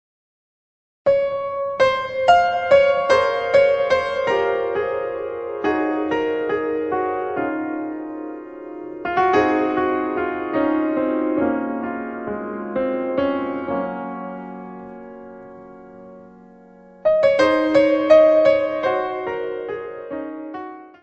: stereo; 12 cm
Music Category/Genre:  New Musical Tendencies